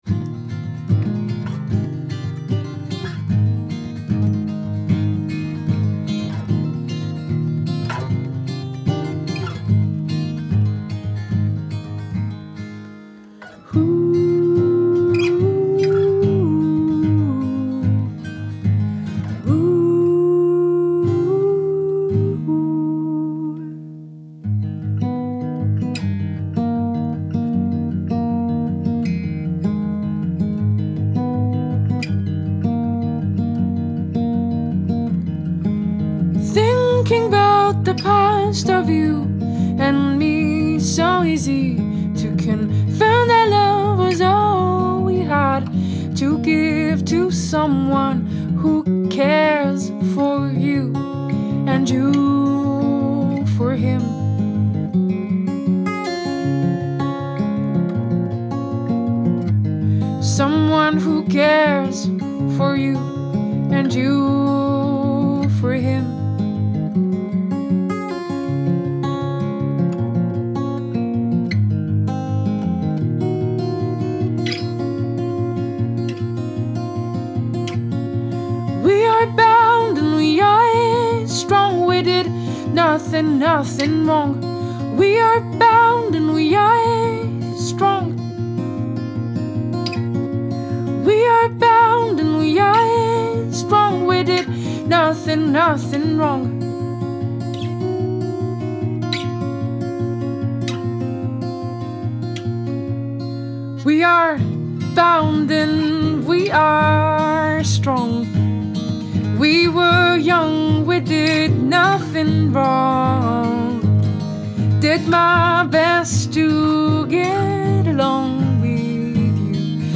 folk romande